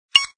BleepClose.aac